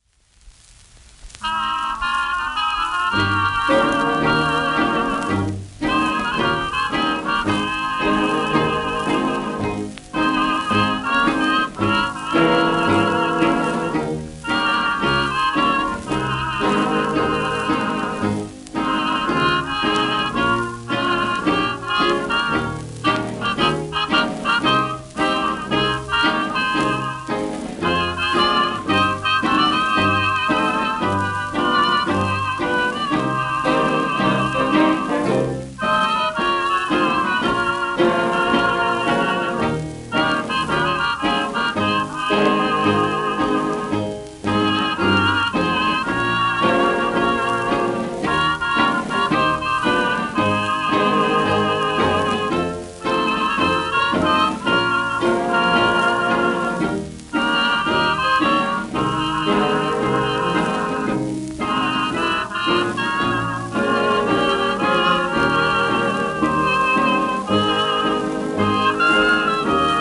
1929年録音